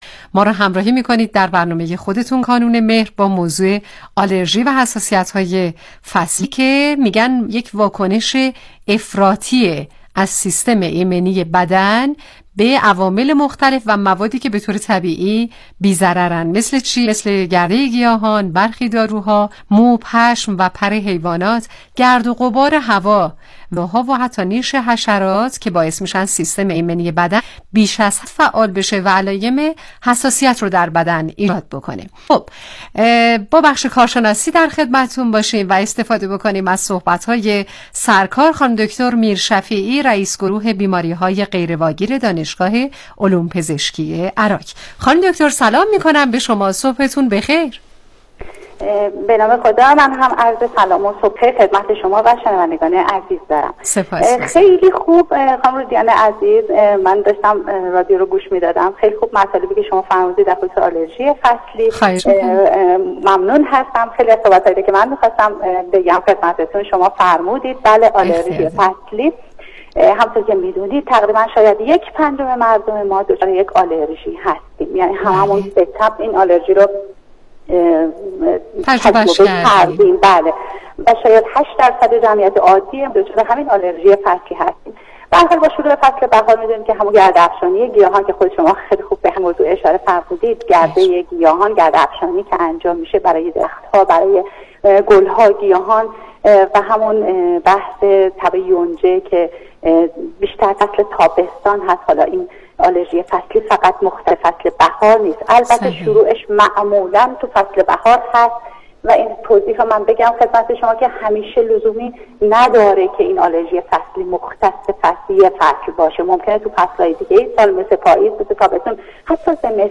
گفتگوی تلفنی